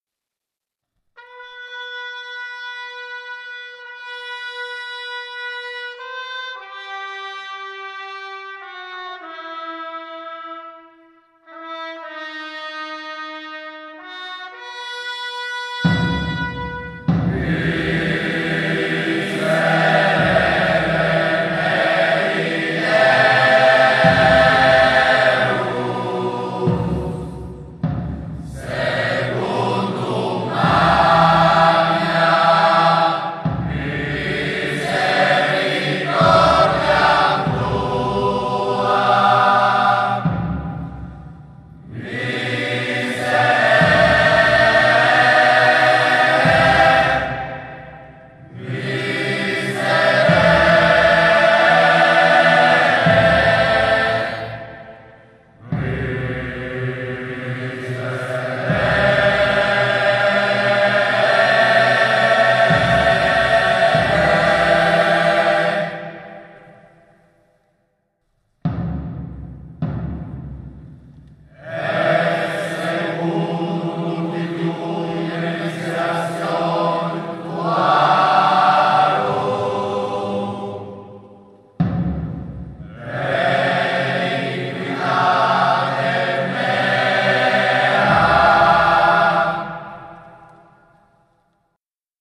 Ovviamente le registrazioni sono a livello amatoriale, per lo più registrate "live" sulle strade, se qualcuno fosse in possesso di registrazioni migliori può, se lo desidera, inviarcele in modo che esse siano messe a disposizione di tutti.